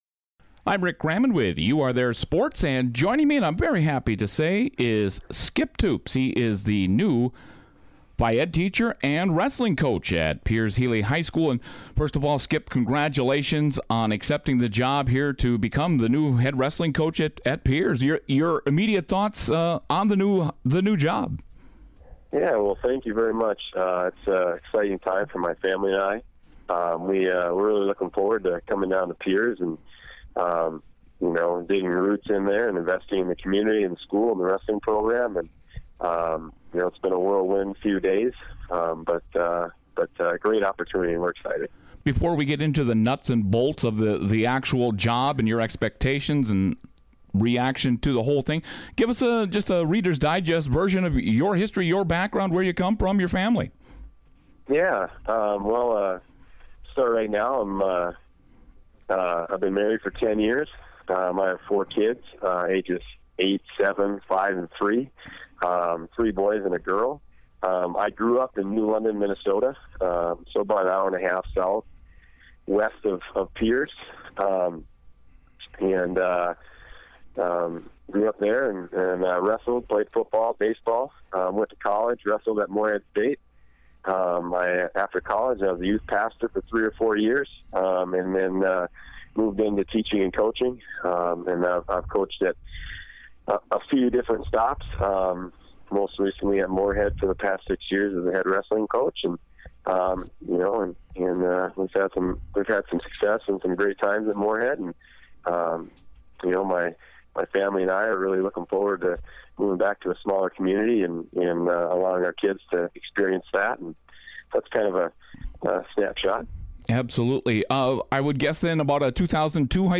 Here is that interview...